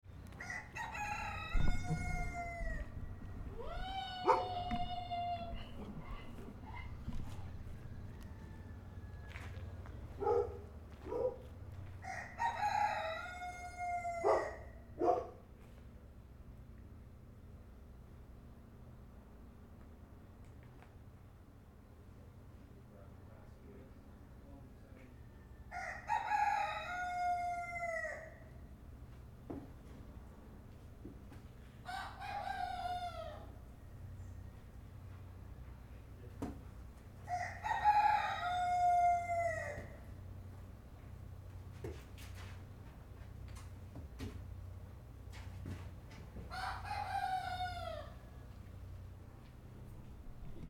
Звуки скотного двора
Атмосферные звуки деревенского заднего двора